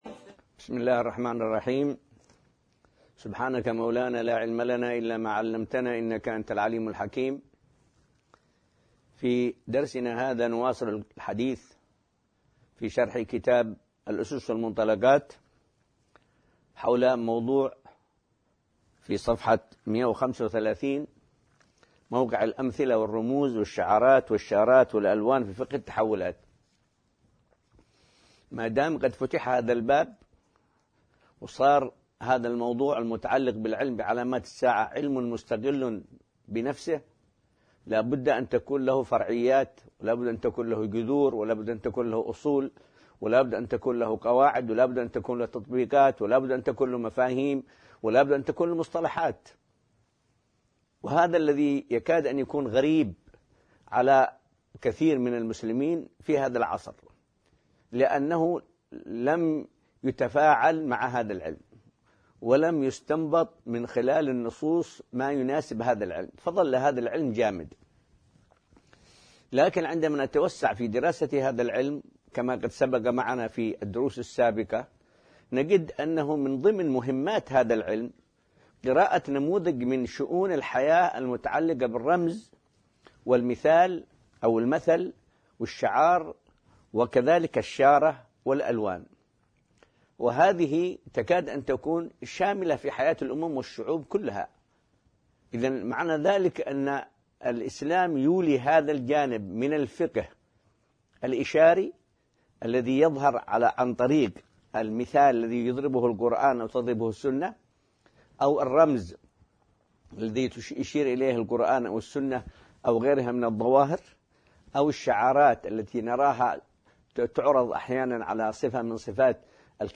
المحاضرة السادسة عشر من سلسلة دروس كتاب الأسس والمنطلقات